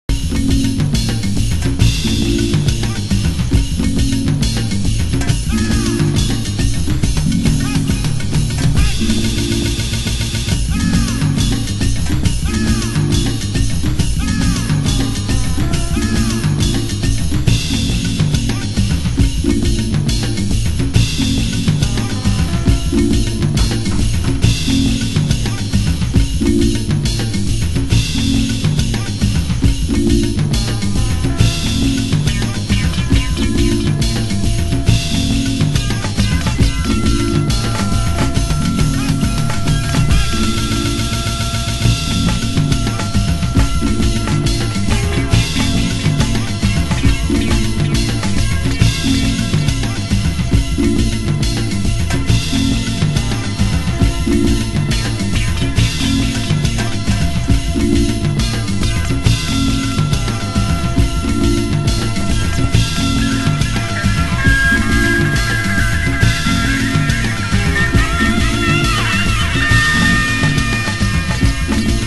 HOUSE MUSIC
盤質：少しチリパチノイズ有/ラベルに少ししみ汚れ有